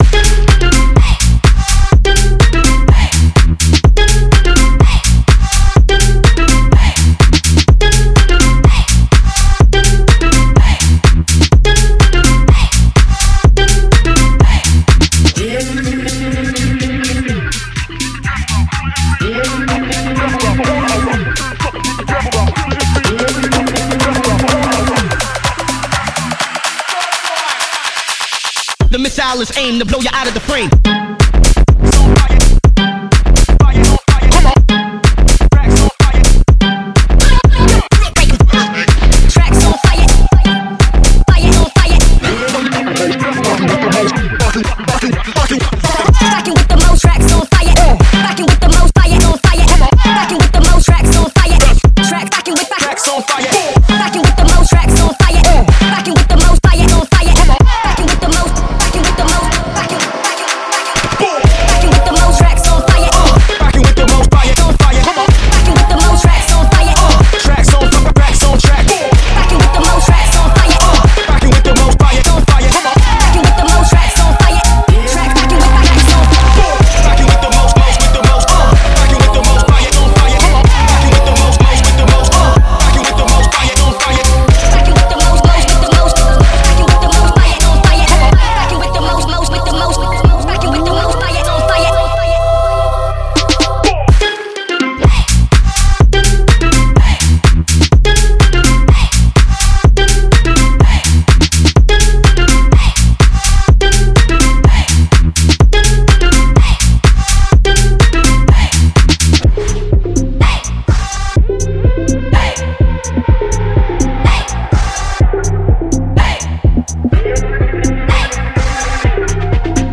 Use of licensed / adapted music